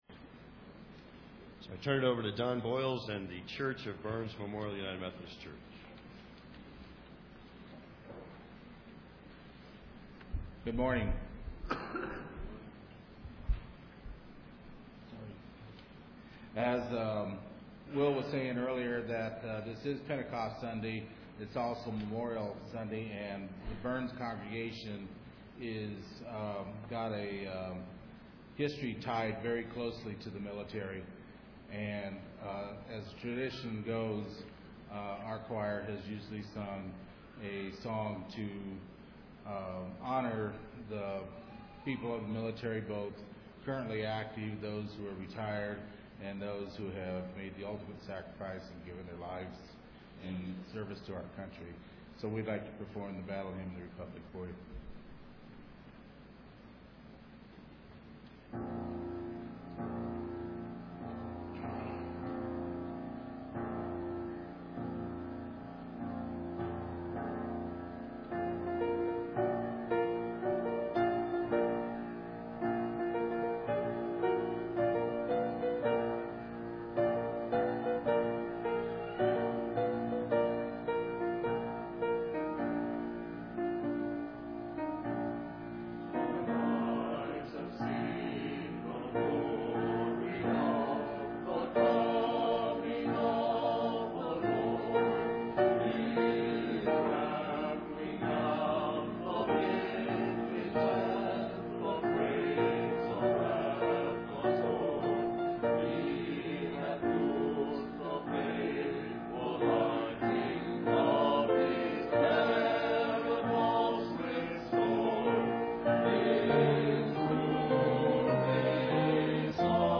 Pentecost Sunday Worship Service
"Battle Hymn of the Republic"             Burns Chancel Choir